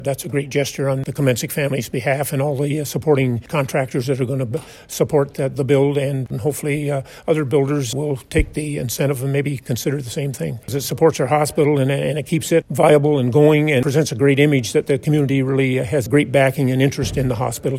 Deputy Mayor Jim Alyea tells Quinte News, it was an easy decision for councillors to make.